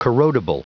Prononciation du mot corrodible en anglais (fichier audio)
Prononciation du mot : corrodible